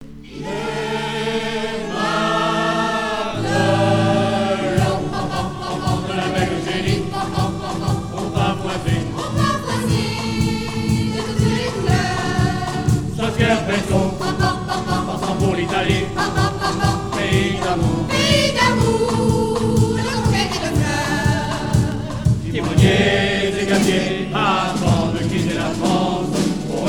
En direct du prieuré Saint-Nicolas